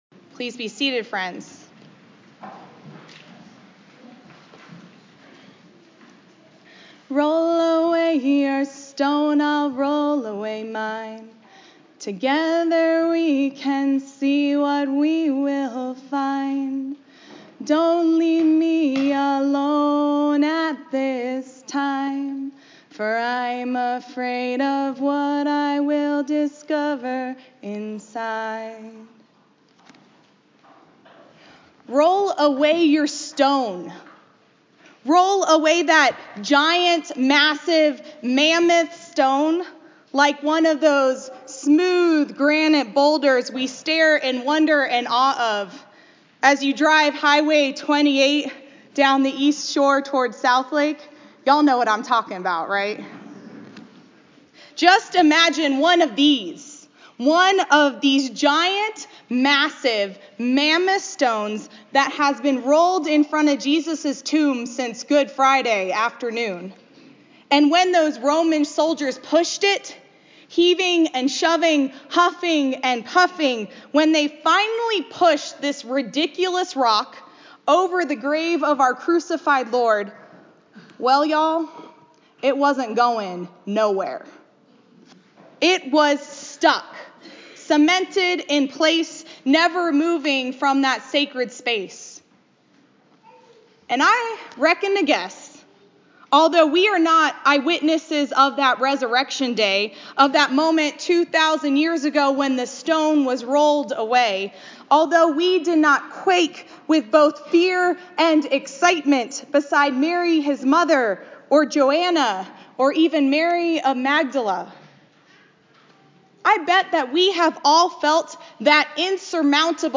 Easter Sunday 2019